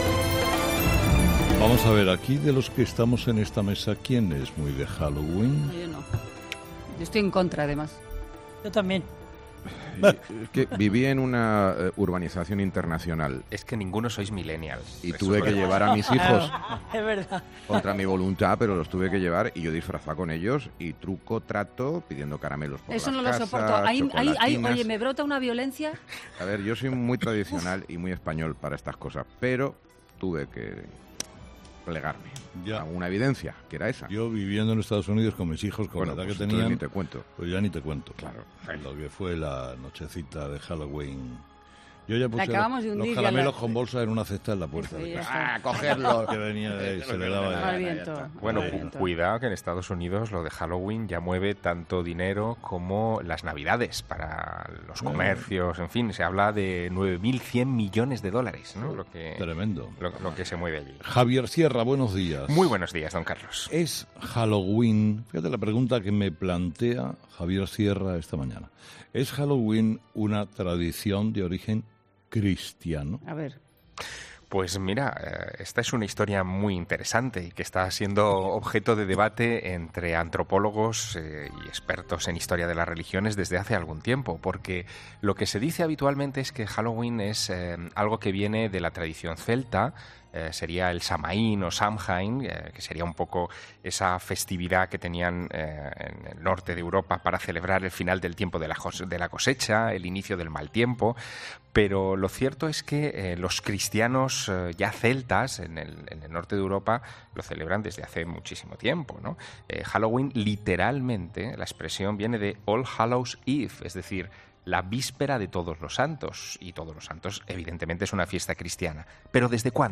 Sierra nos ha contado en su sección el motivo de este rito.